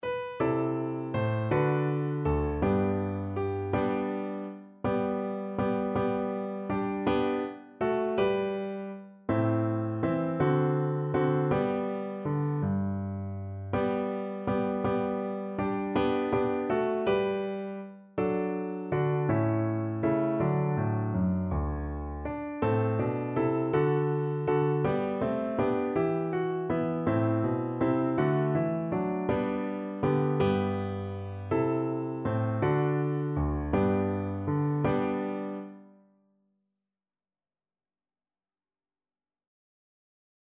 3/4 (View more 3/4 Music)
One in a bar .=c.54
Belgian